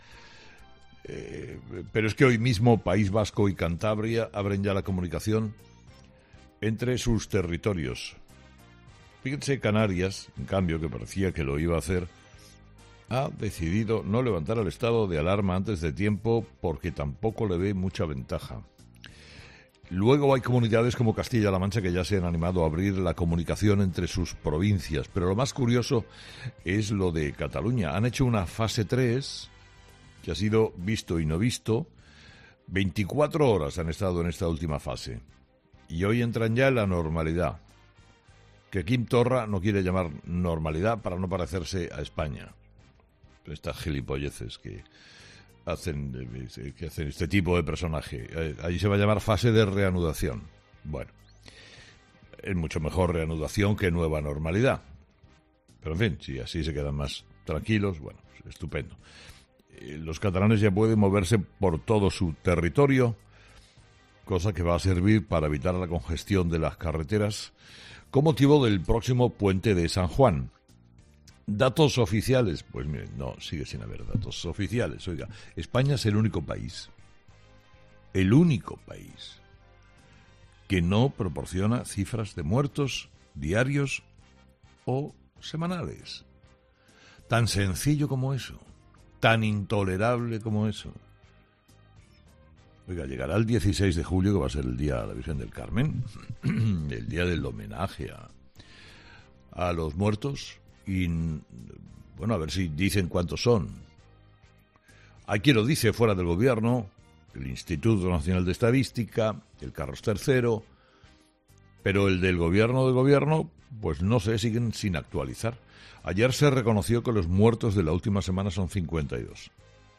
Carlos Herrera, director y presentador de 'Herrera en COPE', ha comenzado el programa de este viernes, 19 de junio de 2020, analizando la última hora del coronavirus en nuestro país a pocas horas del comienzo de la nueva normalidad en todo el territorio español, menos en Cataluña, donde la Generalitat lo ha nombrado Fase de transición: "Estas cosas de Torra son las tonterías para siempre tener un punto diferenciador con España. Siempre dando la nota".